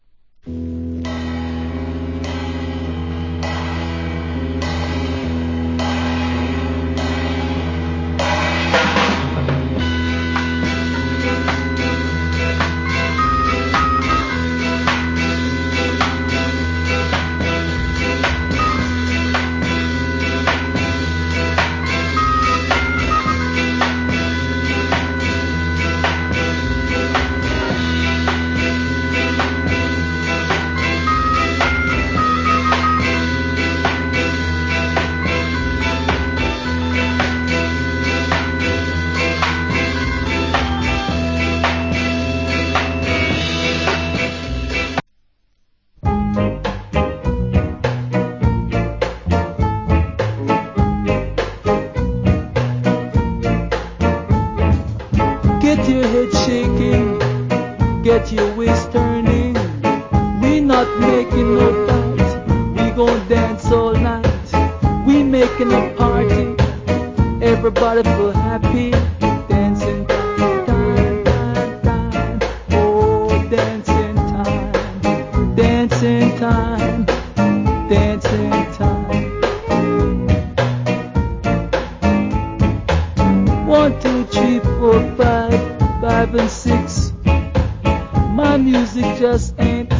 Murder Rock Steady Inst.